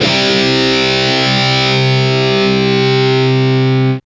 Index of /90_sSampleCDs/Roland LCDP02 Guitar and Bass/GTR_Distorted 1/GTR_Power Chords